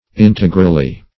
Search Result for " integrally" : Wordnet 3.0 ADVERB (1) 1. in an integral manner ; The Collaborative International Dictionary of English v.0.48: Integrally \In"te*gral*ly\, adv.